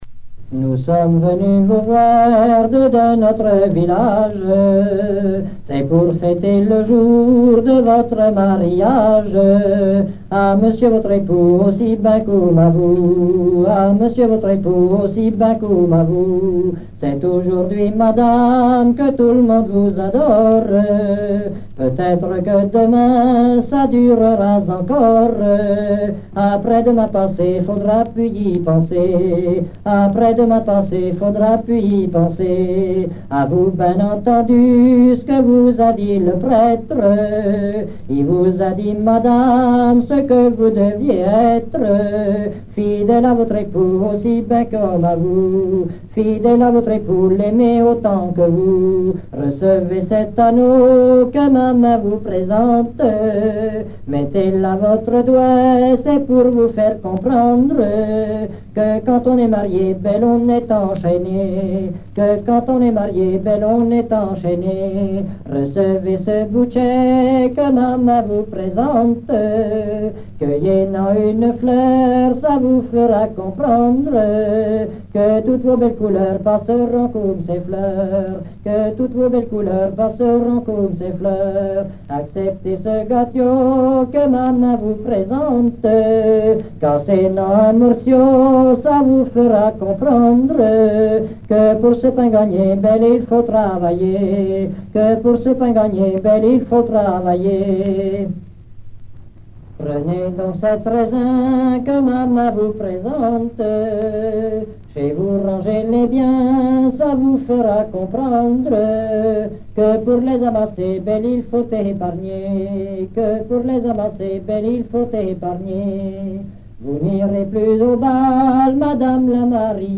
circonstance : fiançaille, noce ;
Genre strophique
Pièce musicale éditée